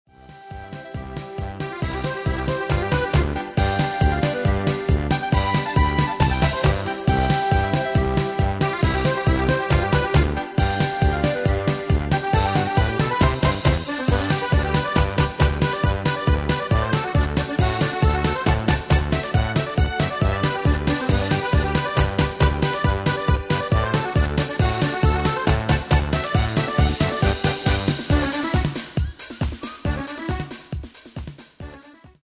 Demo/Koop midifile
Taal uitvoering: Instrumentaal
Genre: Carnaval / Party / Apres Ski
Toonsoort: G